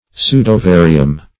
Search Result for " pseudovarium" : The Collaborative International Dictionary of English v.0.48: Pseudovary \Pseu*do"va*ry\, n.; pl.
pseudovarium.mp3